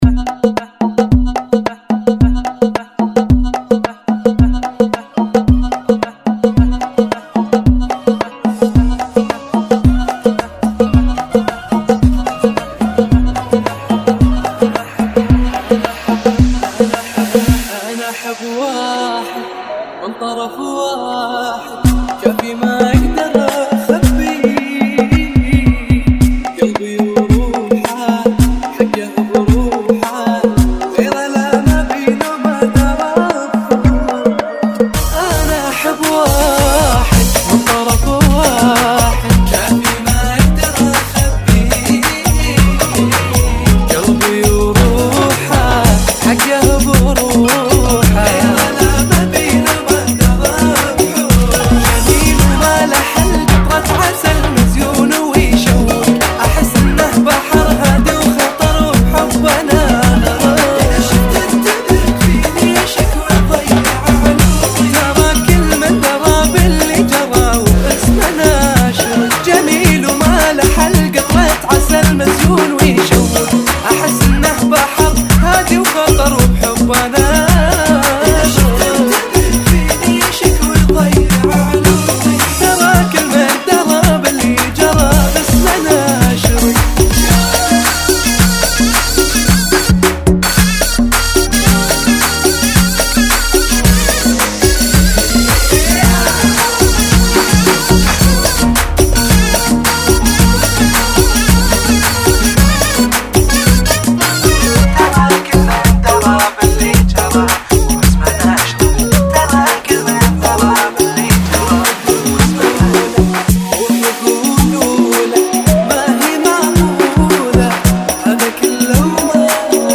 Funky [ 110 Bpm